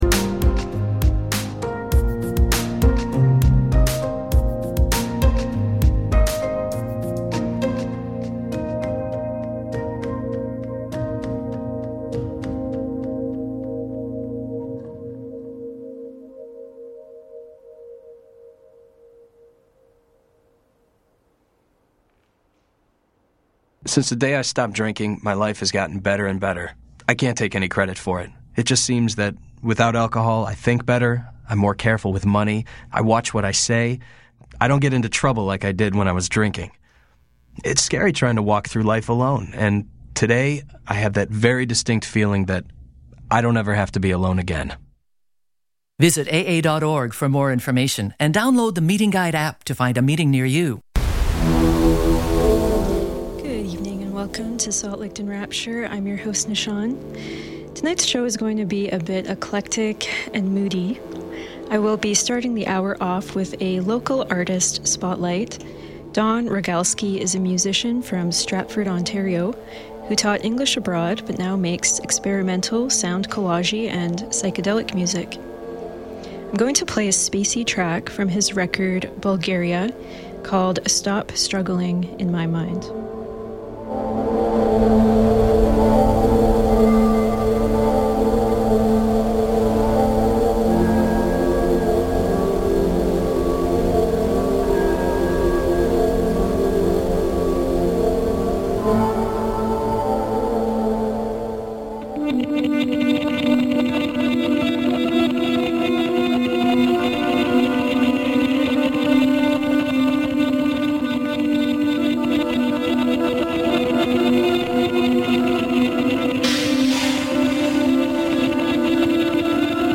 Through an eclectic mix of genres, this station is concerned with thematic and conceptual mixes that create an intense and intriguing listening experience. Featuring both known and lesser known musicians and poets, a special emphasis is placed on oddball, enigmatic outsider artists whose work evokes Lynchian dreamscapes and feelings of alienation, nostalgia, melancholy, existential unease, and yearning.